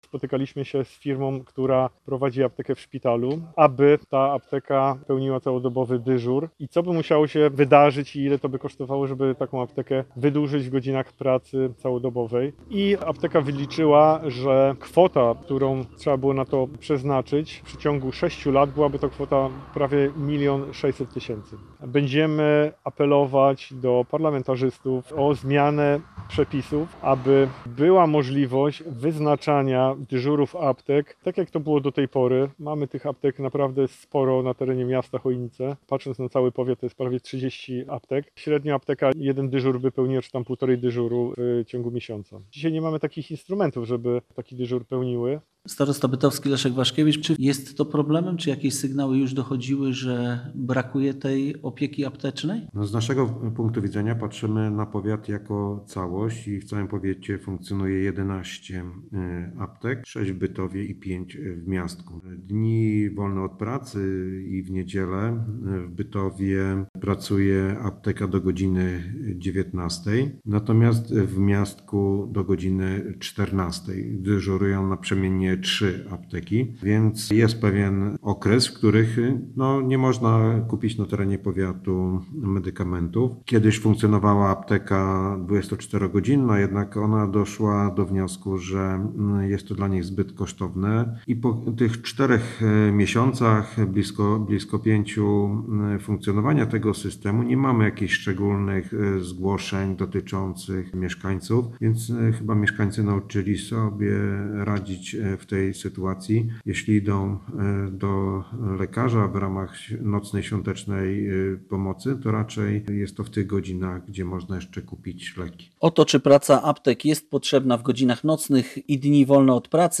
– Koszty, jakie musielibyśmy ponieść, są ogromne – wyjaśnia Marek Szczepański starosta chojnicki.